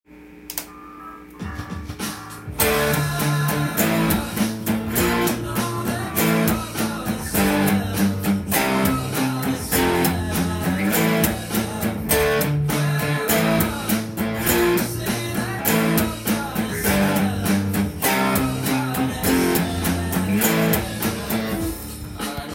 音源にあわせて譜面通り弾いてみました
パワーコードでカンタンに弾けるように表記してみました。
keyがFになるのでFのダイアトニックコードで構成されています。
ブリッジミュートをすると低音が強調されてカッコいい
リズムは全て８分音符なのでひたすら８ビートを刻む感じで